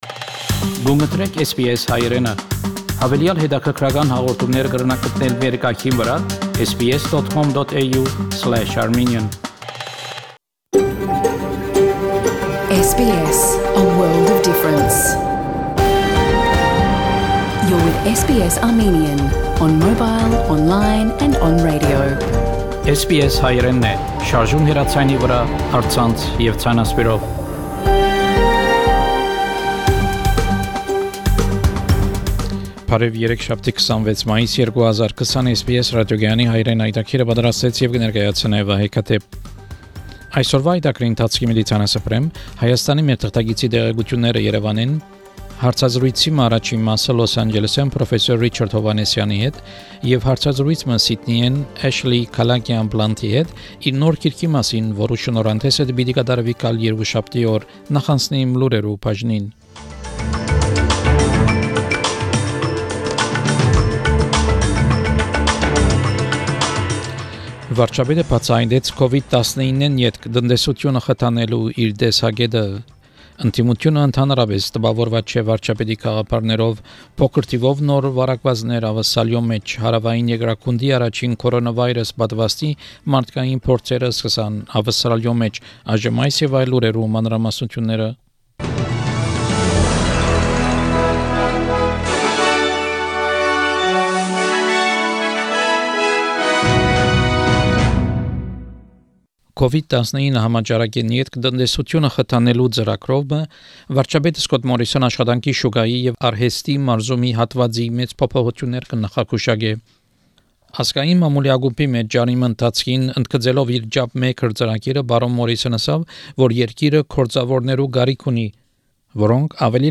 SBS Armenian news bulletin – 26 May 2020